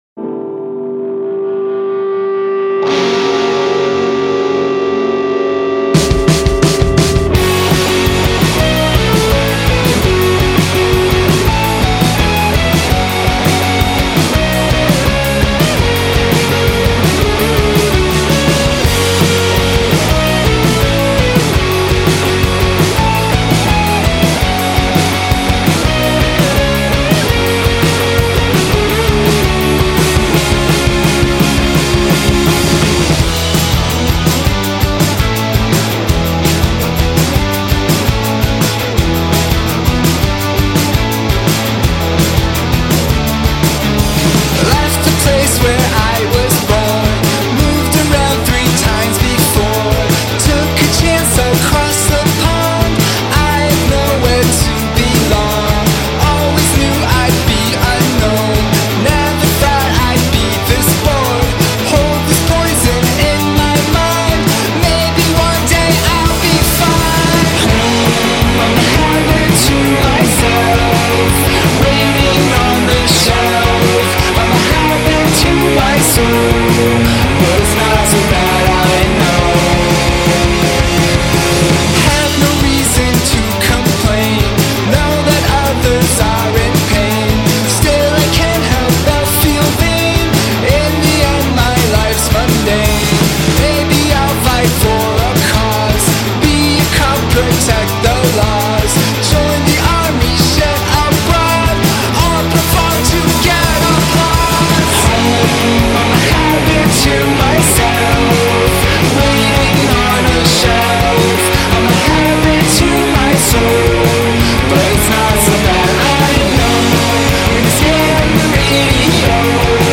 post-punk quartet
Guitar
Bass
Drums